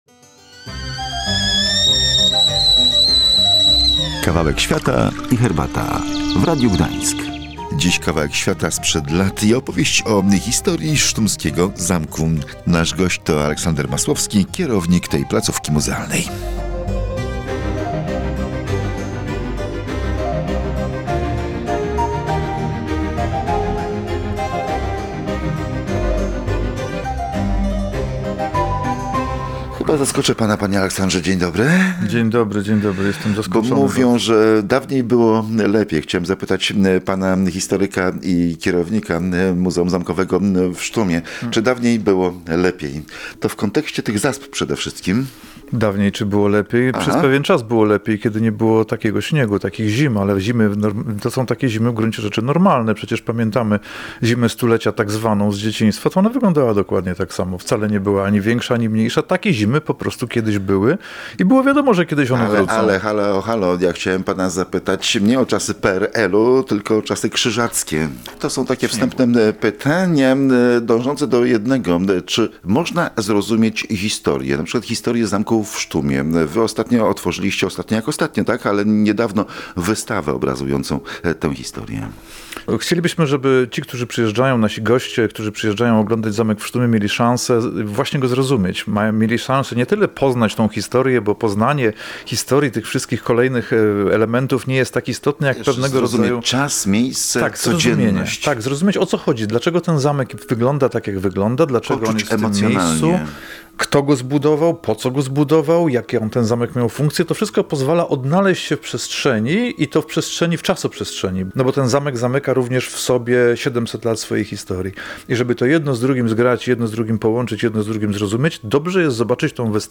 W audycji odwiedzamy Zamek w Sztumie będący oddziałem Muzeum Zamkowego w Malborku.